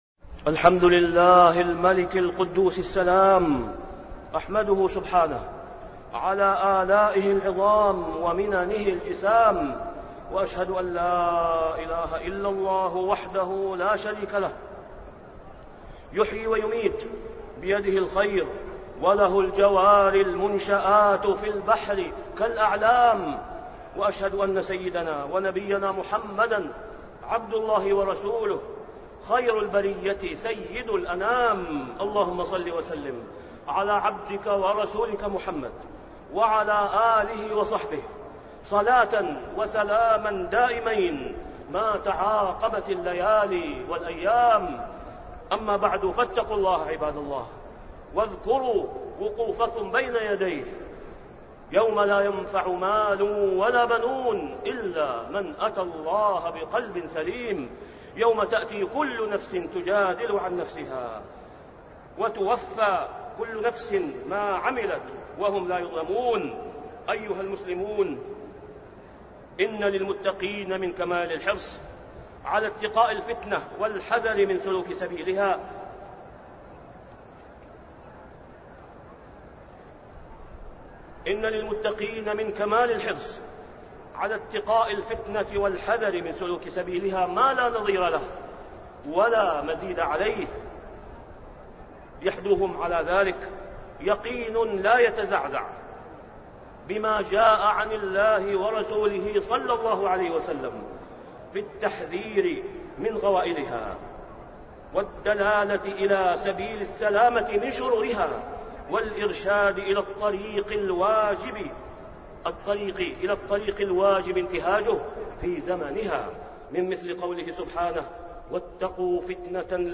تاريخ النشر ٤ شوال ١٤٣٢ هـ المكان: المسجد الحرام الشيخ: فضيلة الشيخ د. أسامة بن عبدالله خياط فضيلة الشيخ د. أسامة بن عبدالله خياط النجاة من الفتن